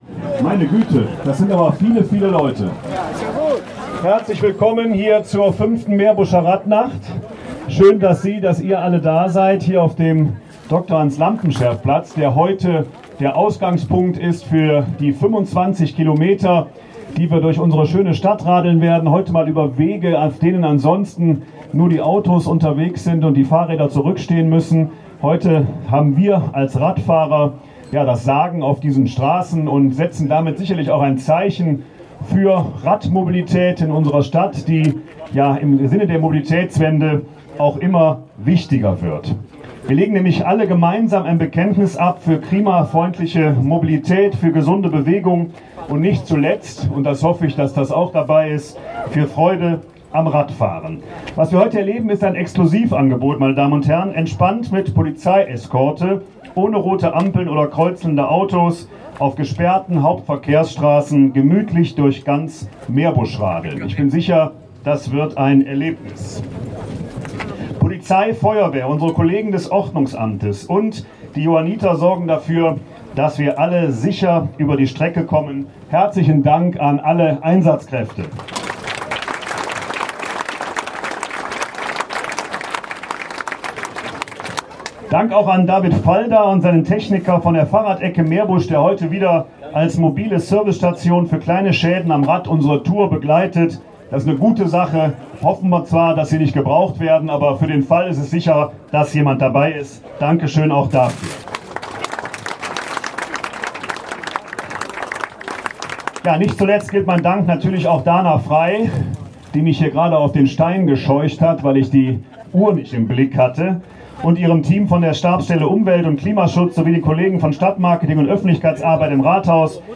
Der wirklich beeindruckte Bürgermeister Christian Bommers eröffnete die Veranstaltung.
Die Auftaktrede von Christian Bommers (Audio 1/2) [MP3]